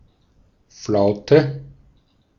Ääntäminen
Ääntäminen US : IPA : [kɑm] UK : IPA : /kɑːm/ US : IPA : /kɑ(l)m/ IPA : /kɔ(ː)m/